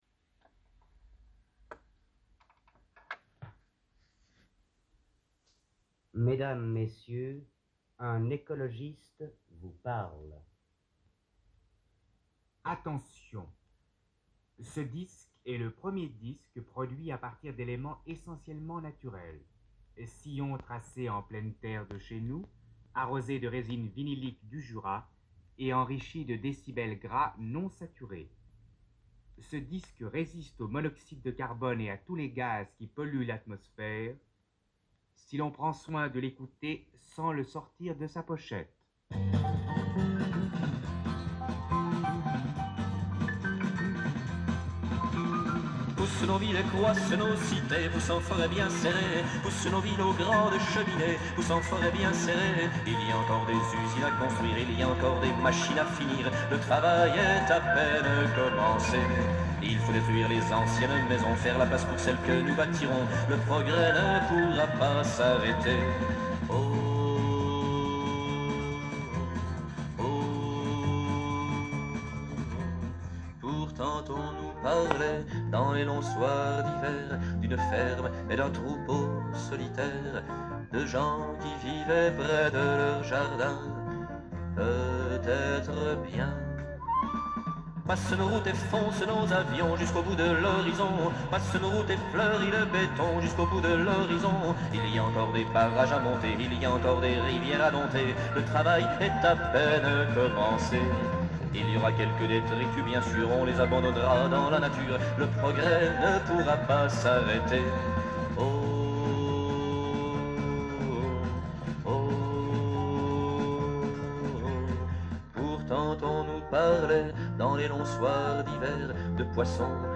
Enregistrement Sound Studio Souncraft Bienne
et Radio Suisse romande, Studio de Lausanne.